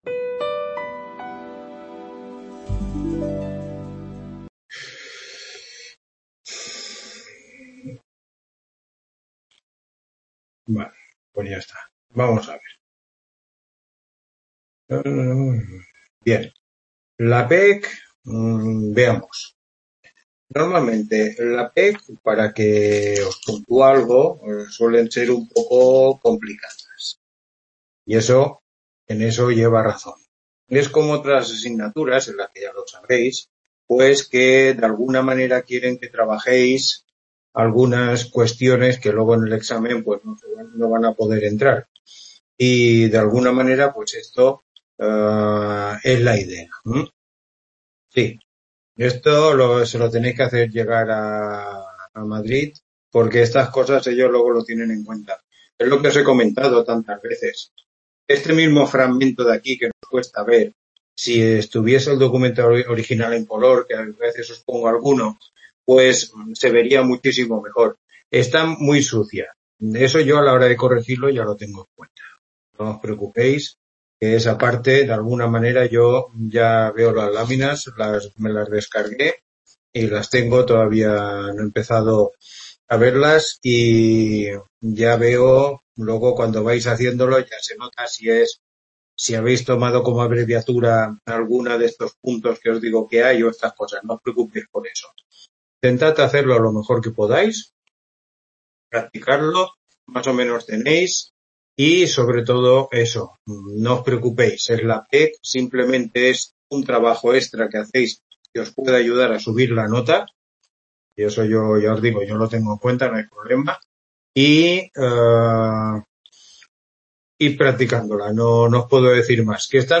Tutoría 8